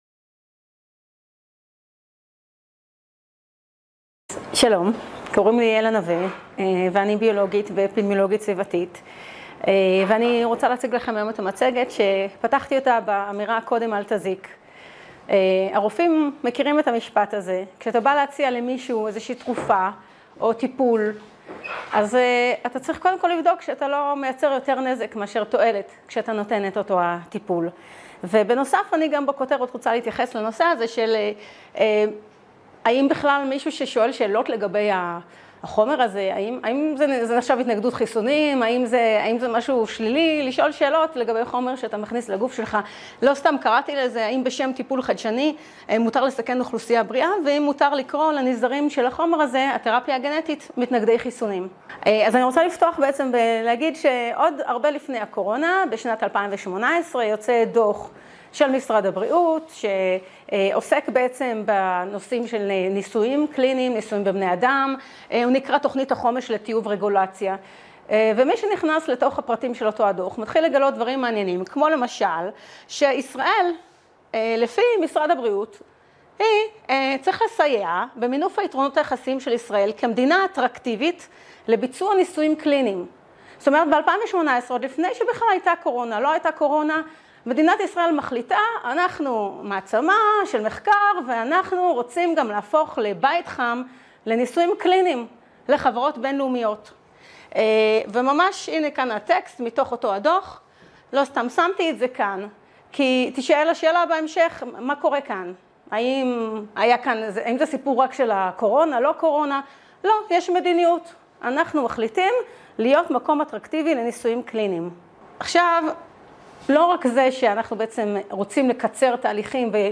הרצאה